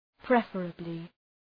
{‘prefərəblı}
preferably.mp3